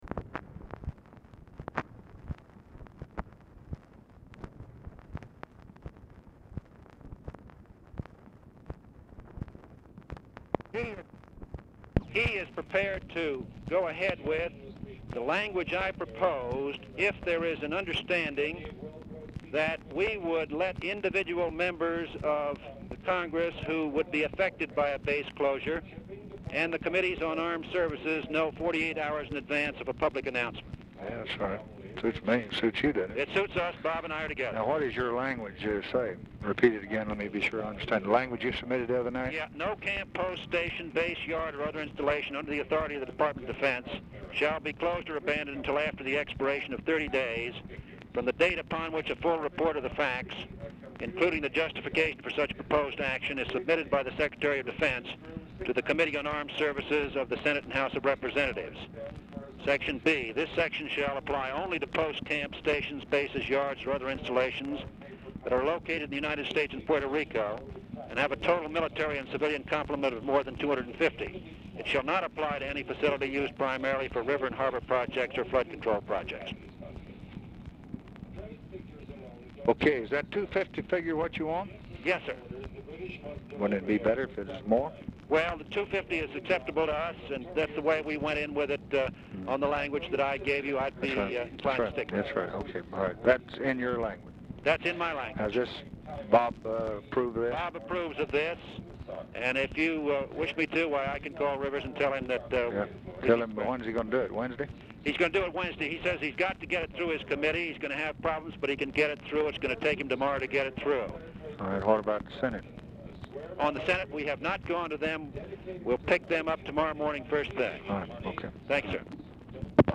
RECORDING STARTS AFTER CONVERSATION HAS BEGUN; TV OR RADIO AUDIBLE IN BACKGROUND
Format Dictation belt
Specific Item Type Telephone conversation